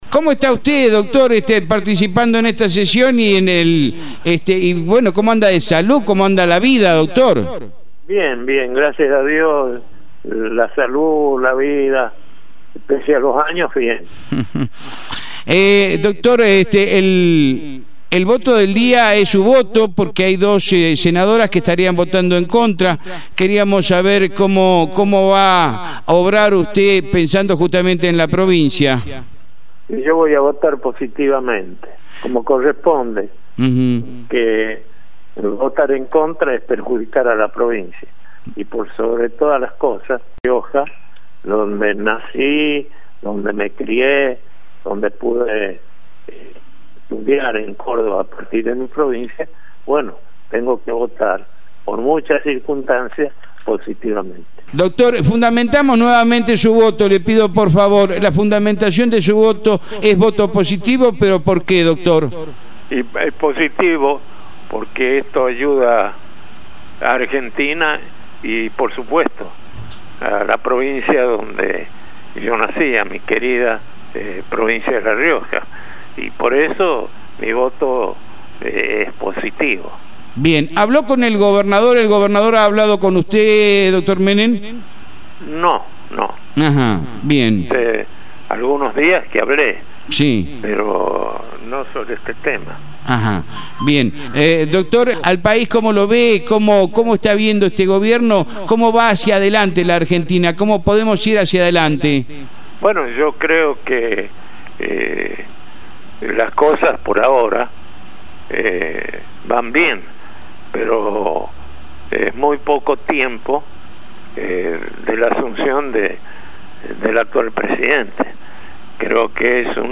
Menem confirmó por Radio Libertad que apoyará el acuerdo
carlos-menem-nota-en-radio-libertad.mp3